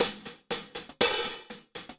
Index of /musicradar/ultimate-hihat-samples/120bpm
UHH_AcoustiHatB_120-03.wav